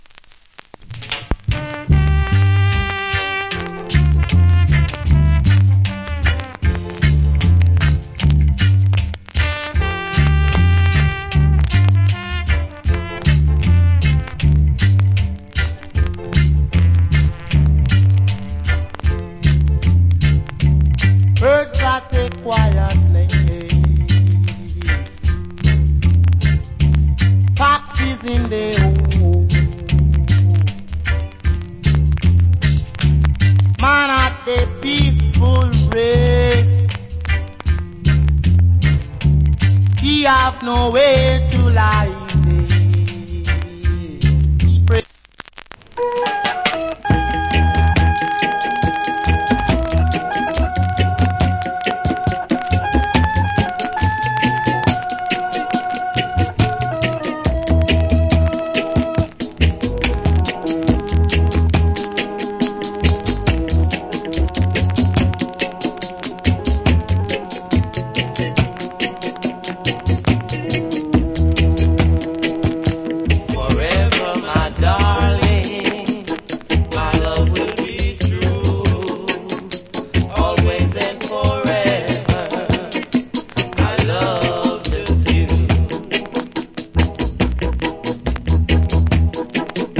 Roots Rock Vocal.